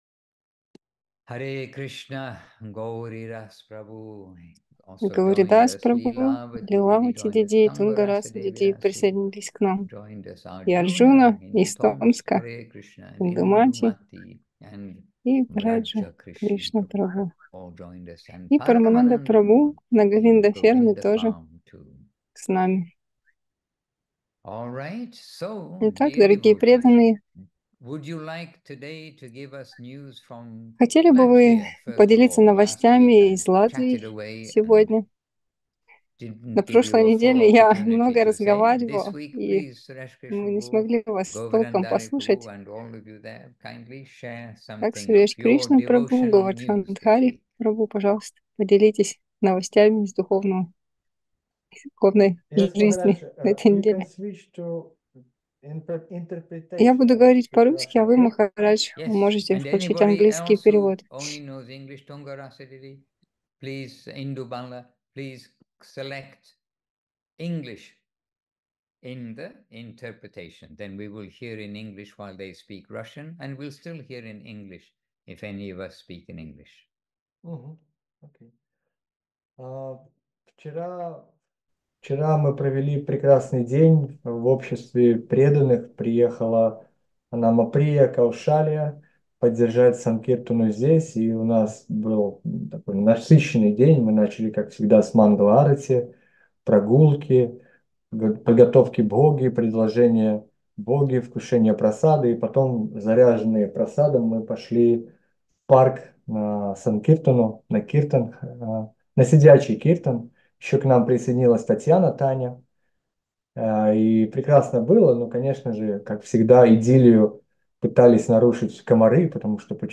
Латвийский зум. 19 мая 2024 года.
Лекции полностью
Проповедь